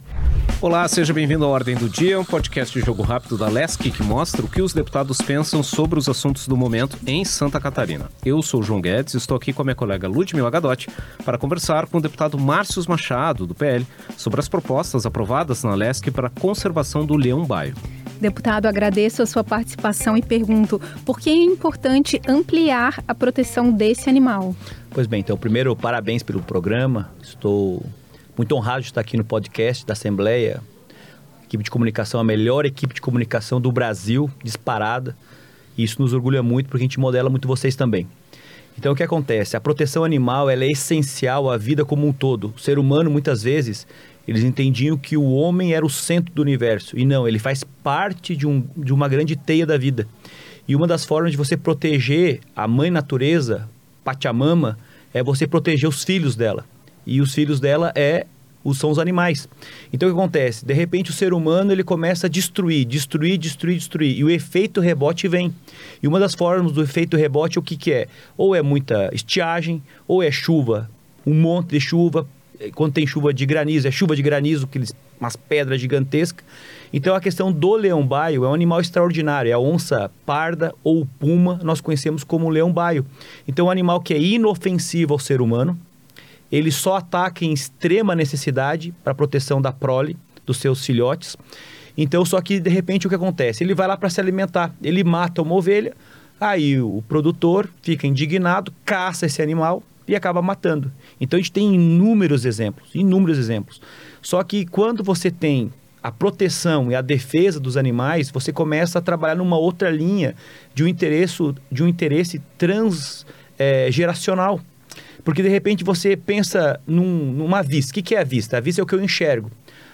O deputado Marcius Machado (PL) fala sobre a importância da proteção do leão-baio, que é o segundo maior felino das Américas e está presente na Serra catarinense.